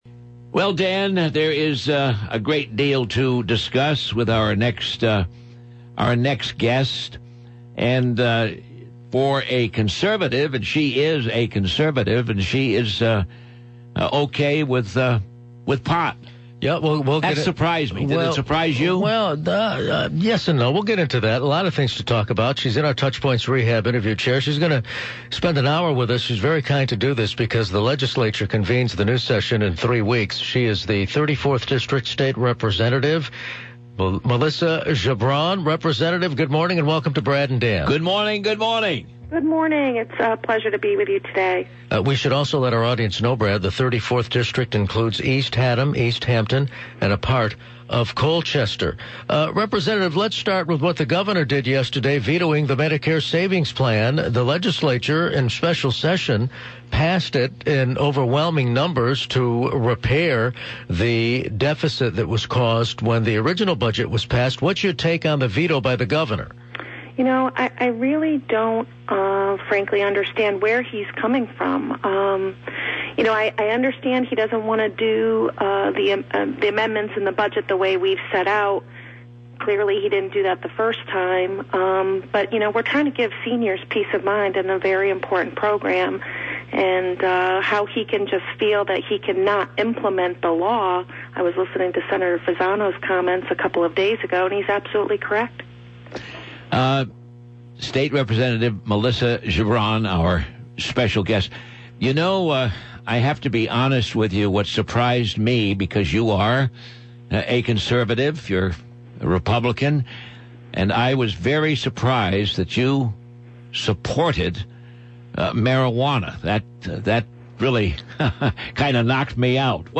In a wide-ranging interview